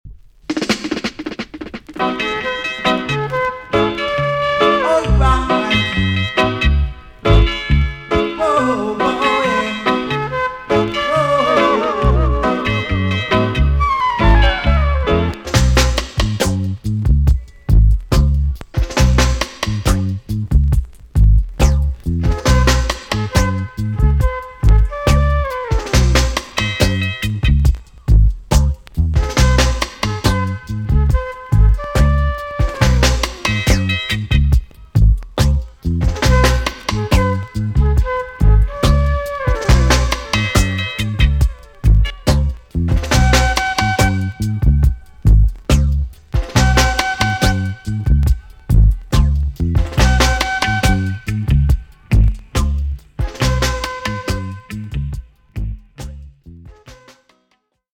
TOP >DISCO45 >80'S 90'S DANCEHALL
B.SIDE Version
EX- 音はキレイです。